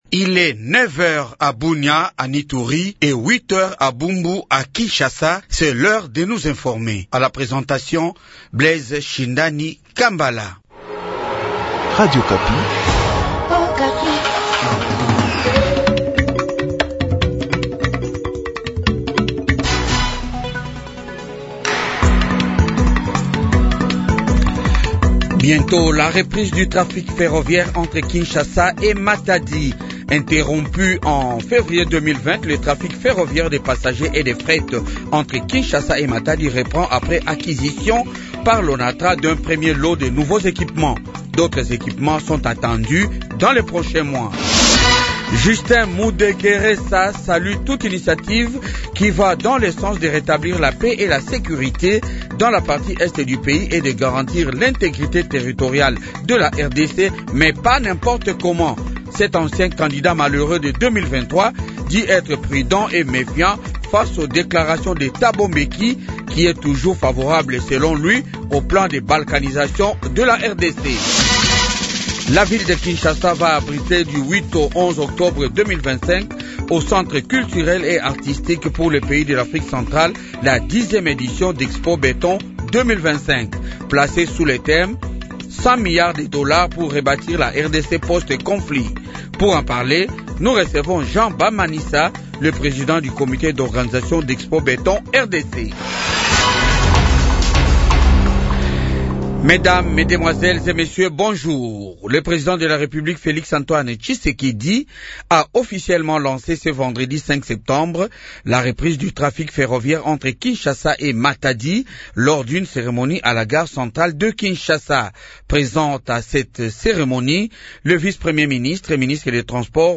Journal Francais matin 8H